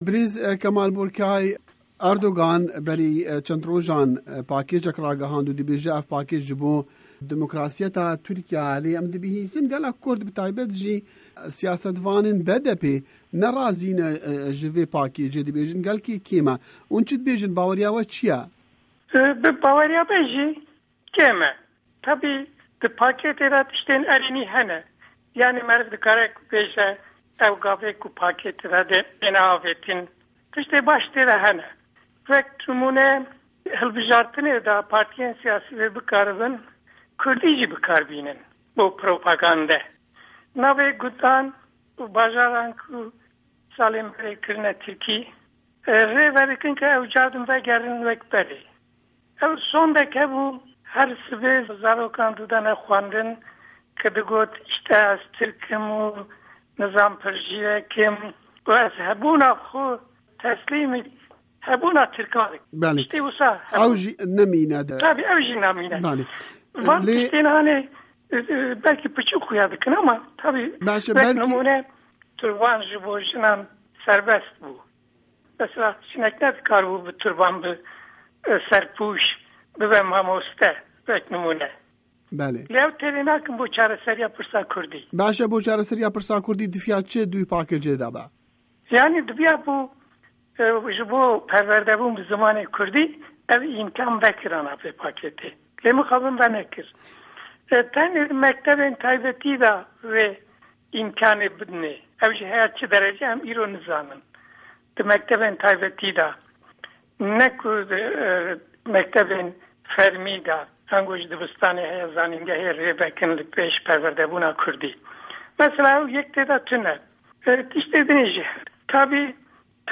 Di hevpeyvînekê de ligel Dengê Amerîka, Kemal Burkay, Serokê partîya Maf û Azadîyan (HAK-PAR) got, pakêca Erdogan başî têde ne, lê ne bes in û jibo derdê Kurdan nabe çareserî.
Hevpeyvîn digel Kemal Burkayî